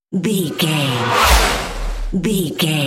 Whoosh electronic metal
Sound Effects
Atonal
futuristic
intense